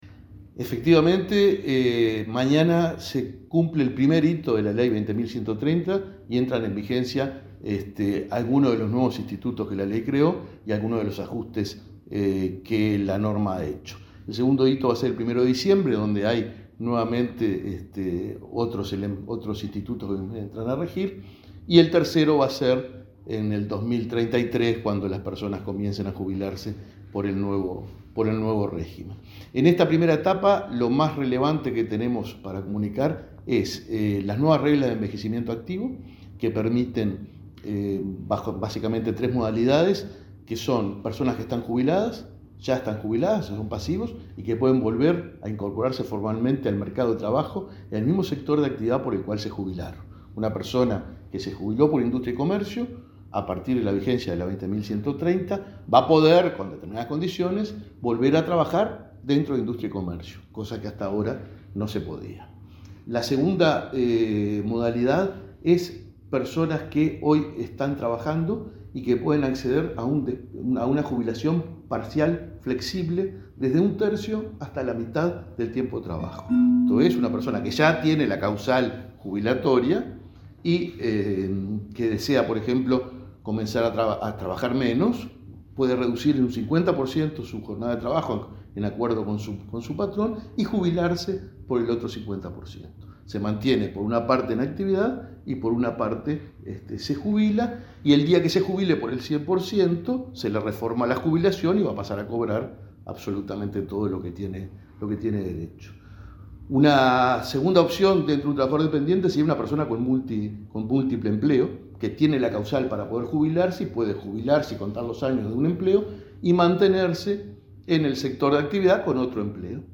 Entrevista al presidente del BPS, Alfredo Cabrera